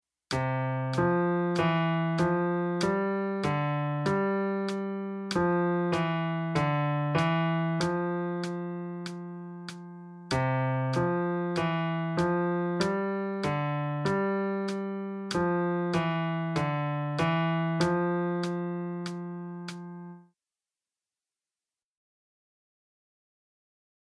Voicing: Electric Piano